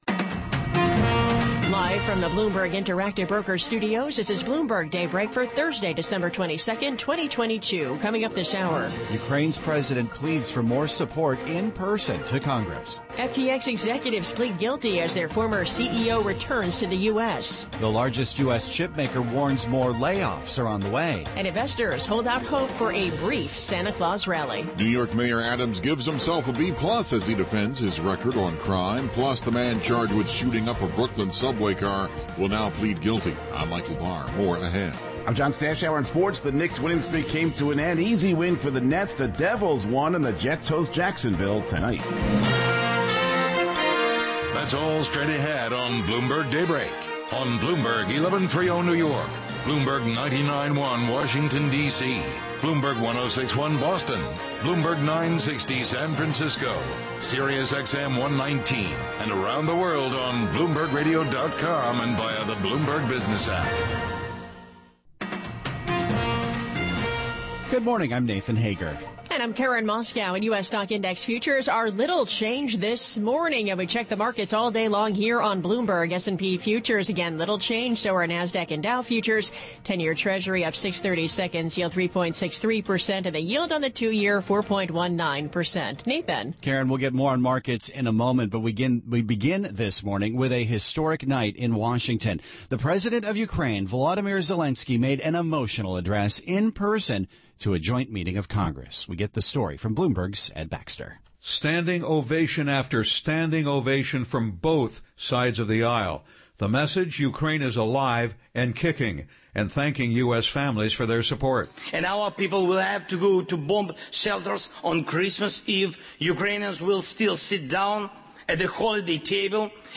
Bloomberg Daybreak: December 22, 2022 - Hour 1 (Radio)